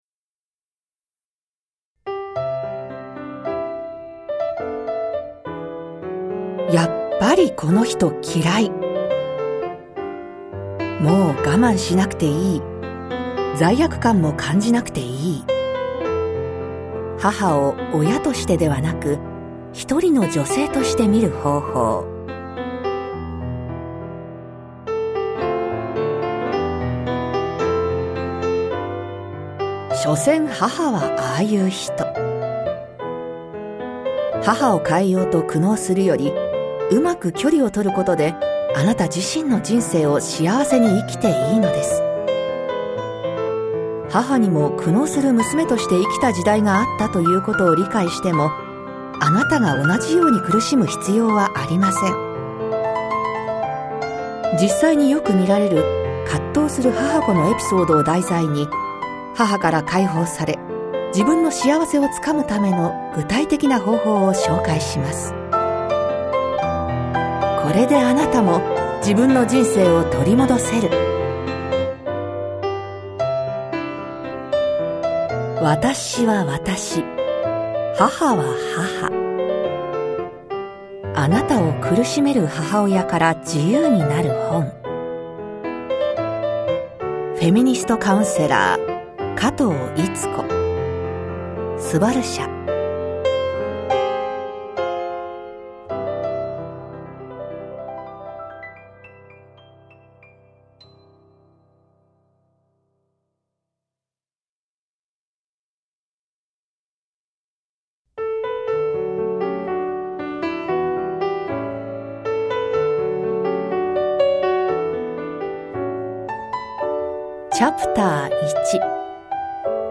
[オーディオブック] 私は私。母は母。